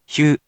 In romaji, 「ひゅ」 is transliterated as 「hyu」which sounds sort of like the saying the English word「hue」or 「hugh」.